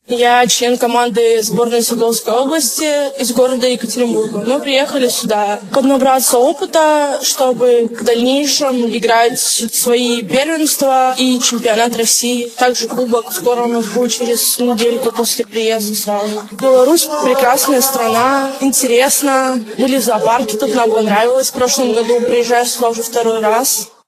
Своими впечатлениями поделилась игрок из России